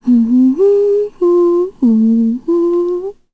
Ophelia-vox-Hum_kr.wav